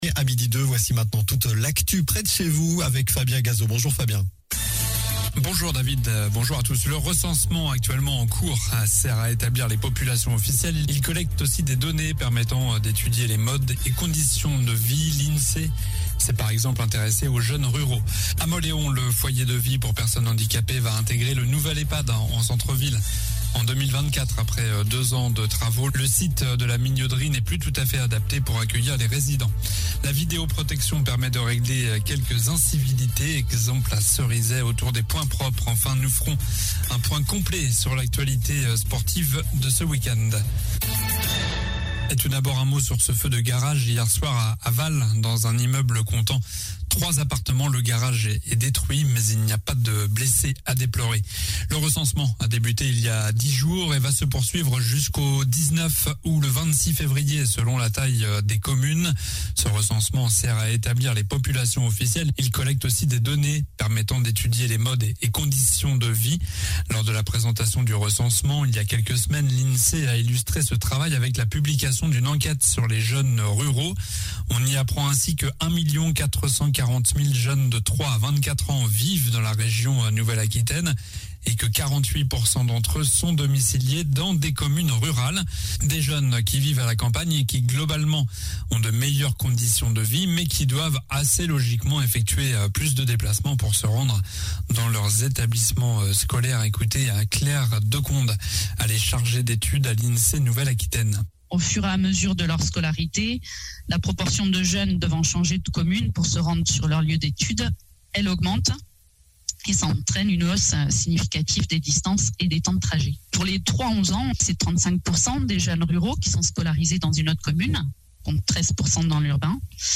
Journal du lundi 31 janvier (midi)